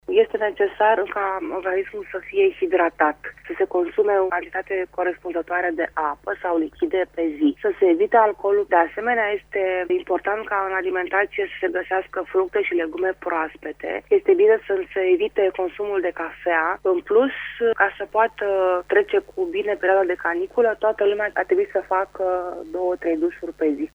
medic.mp3